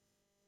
Hi-Hat Closed
A tight, crisp closed hi-hat click with minimal sustain and clean attack
hi-hat-closed.mp3